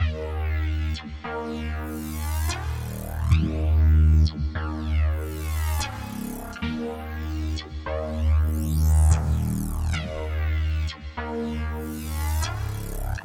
藐视这个 合成器 145bpm
描述：陷阱和科幻的结合。沉重的打击和神秘感。使用这些循环来制作一个超出这个世界范围的爆炸性节目吧 :)D小调
Tag: 145 bpm Trap Loops Synth Loops 2.23 MB wav Key : D